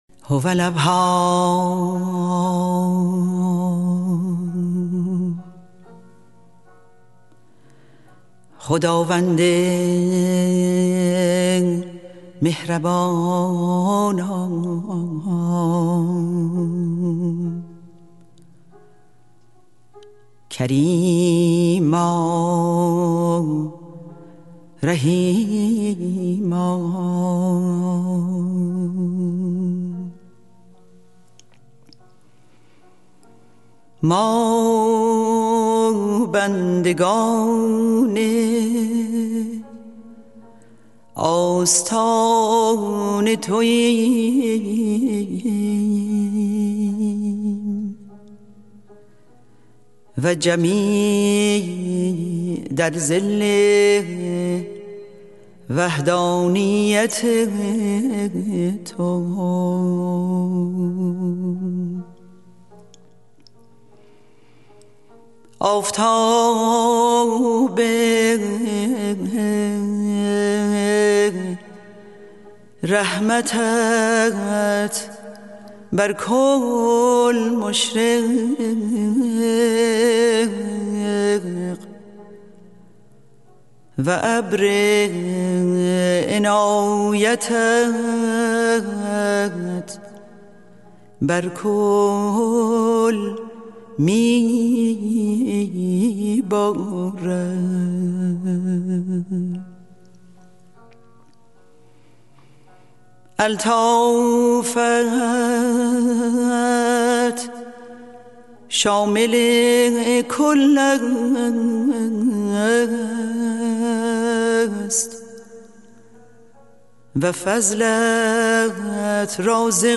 سرود - شماره 2 | تعالیم و عقاید آئین بهائی